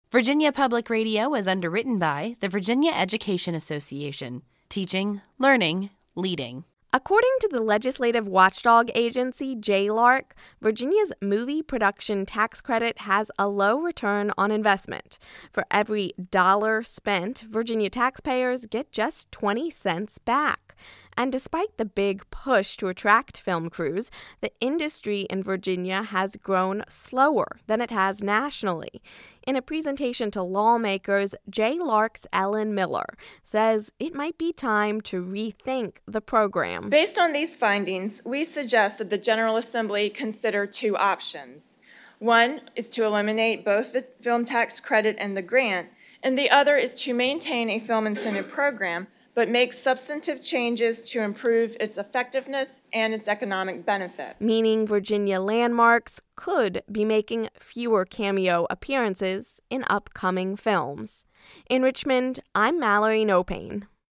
Film and Movies | Virginia Public Radio